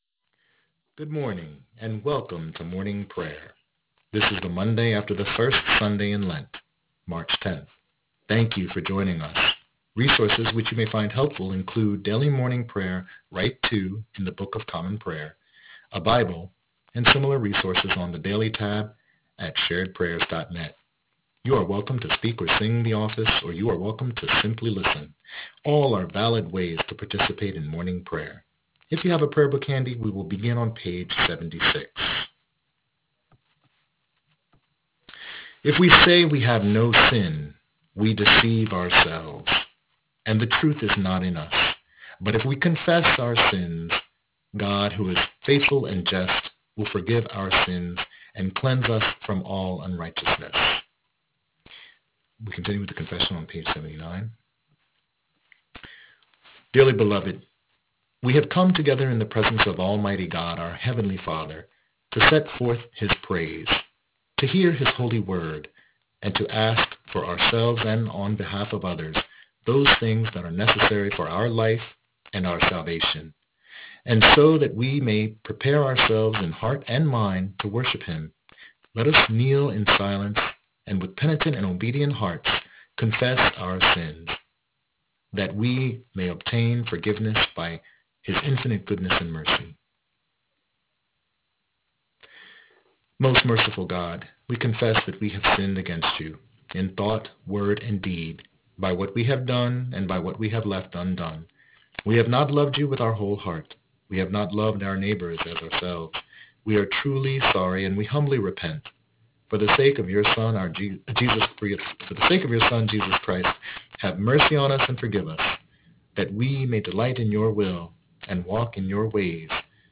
Morning Prayer for Tuesday, 9 February 2021